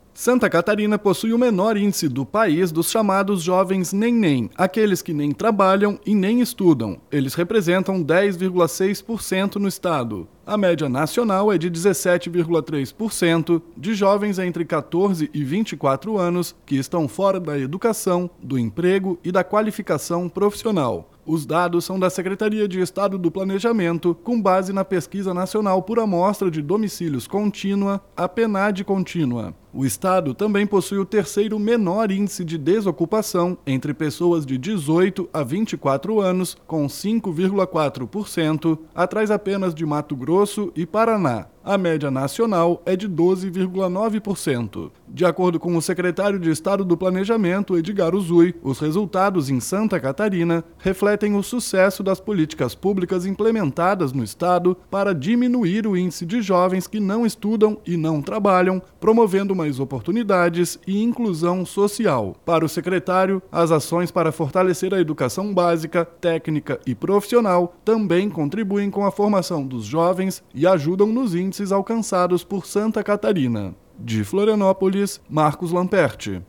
BOLETIM – Santa Catarina tem a menor proporção de jovens do país que nem trabalham nem estudam